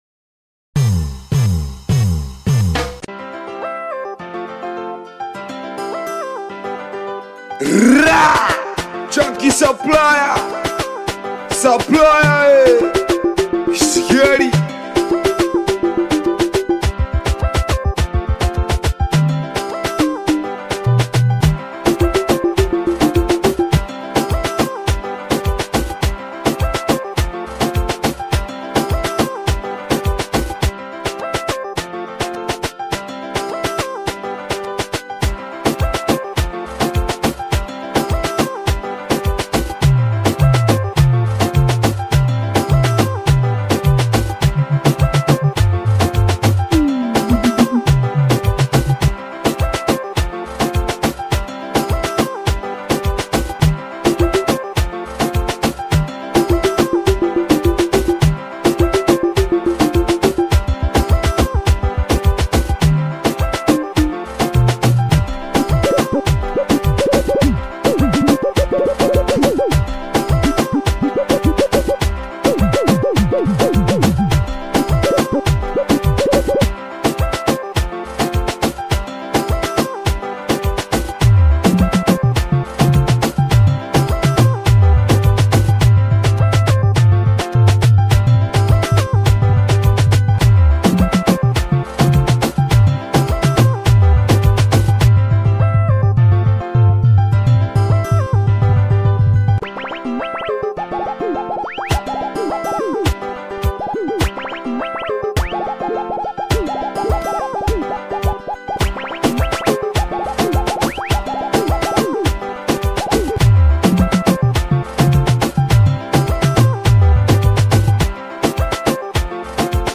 SINGELI BEAT